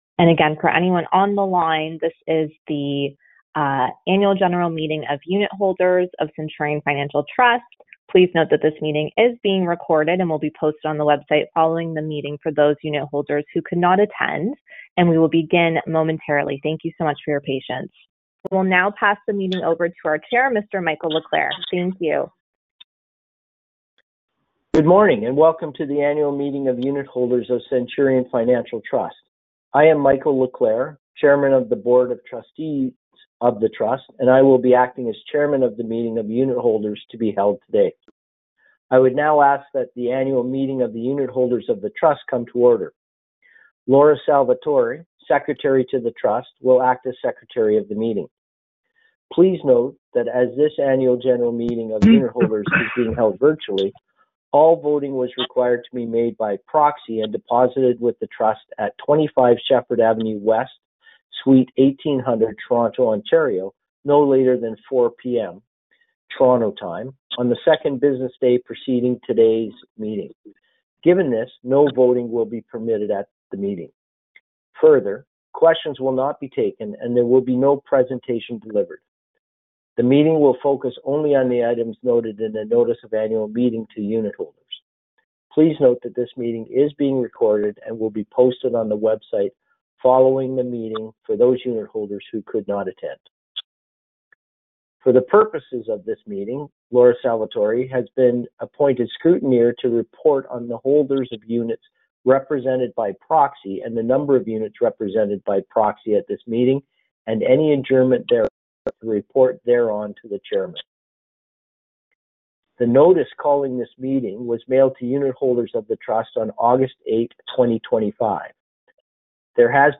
ANNUAL GENERAL MEETING
The Annual General Meeting of Unitholders of Centurion Apartment Real Estate Investment Trust (“REIT”) and the Annual General Meeting of Unitholders of Centurion Financial Trust (“CFIT”) were held via conference call on September 18, 2025.